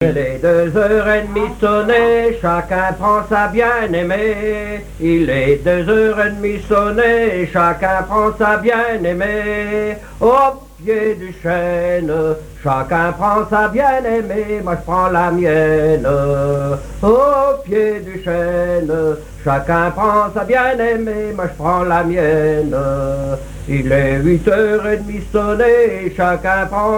Fonction d'après l'analyste gestuel : à marcher
Usage d'après l'analyste circonstance : fiançaille, noce
Genre énumérative
Pièce musicale inédite